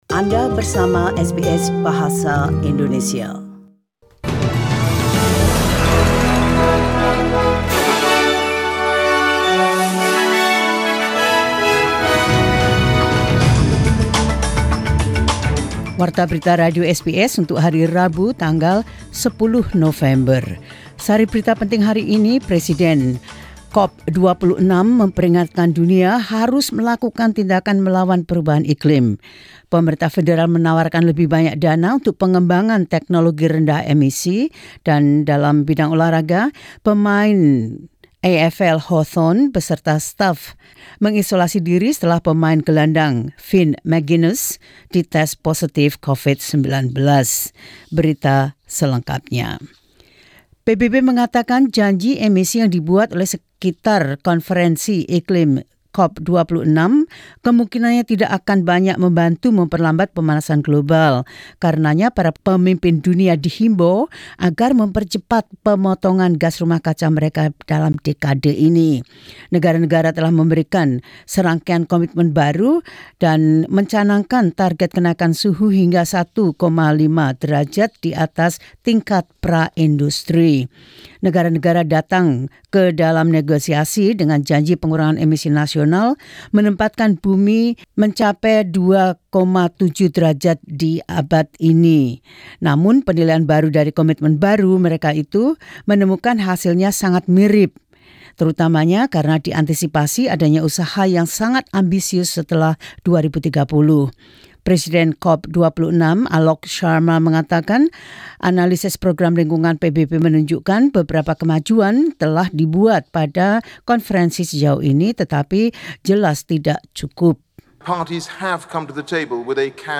Warta Berita Radio SBS Program Bahasa Indonesia – 10 Nov 2021
SBS News Indonesian Program – 10 Nov 2021 Source: SBS